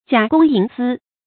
假公营私 jiǎ gōng yíng sī
假公营私发音
成语注音ㄐㄧㄚˇ ㄍㄨㄙ ㄧㄥˊ ㄙㄧ